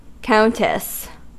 Ääntäminen
Ääntäminen US Tuntematon aksentti: IPA : /ˈkaʊntɨs/ Haettu sana löytyi näillä lähdekielillä: englanti Määritelmät Substantiivit The wife of a count or earl .